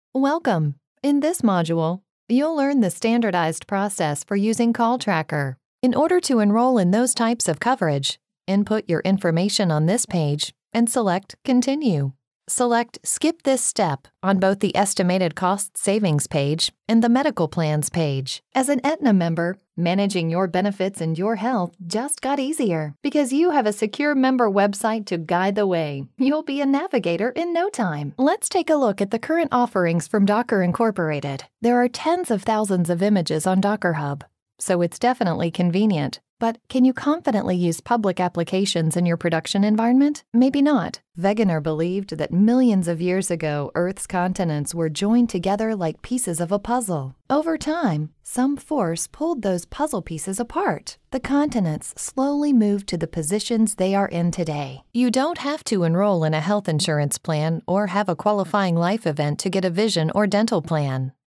Female Voice Over, Dan Wachs Talent Agency.
Warm, Authoritative, Spokesperson.
eLearning